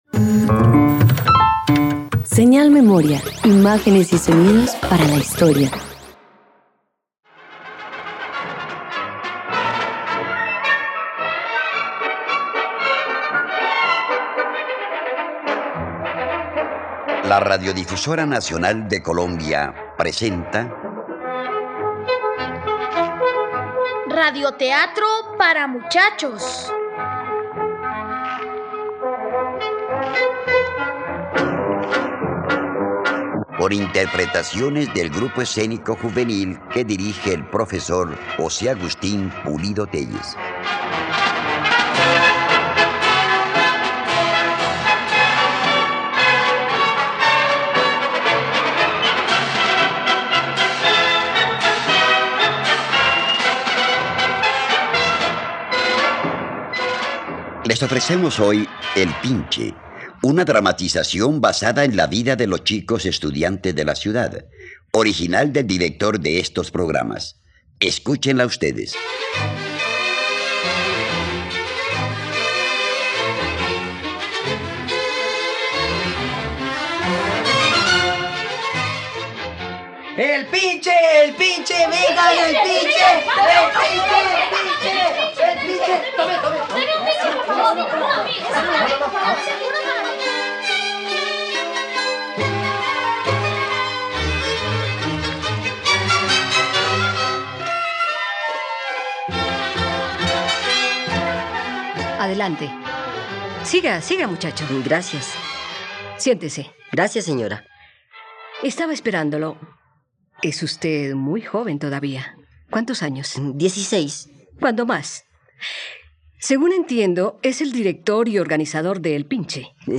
La nave del futuro - Radioteatro dominical | RTVCPlay
radioteatro